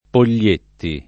[ pol’l’ % tti ]